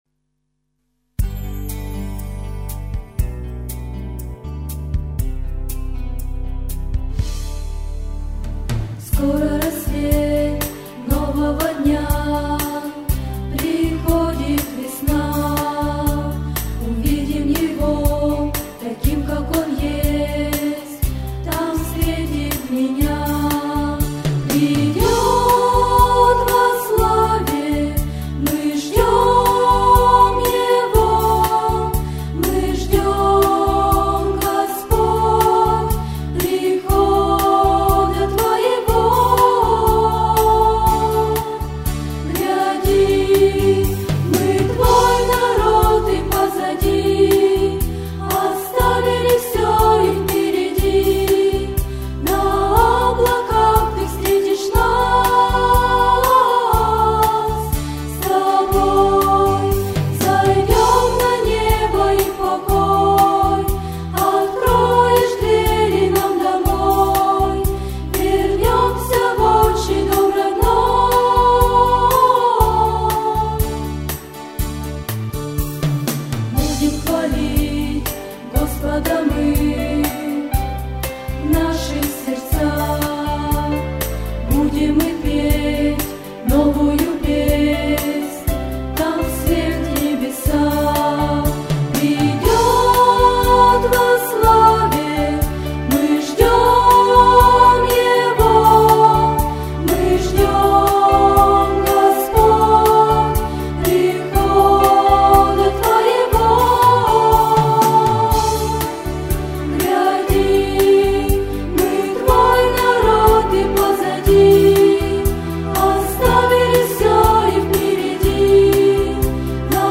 Всі мінусовки жанру Bossa-nova
Плюсовий запис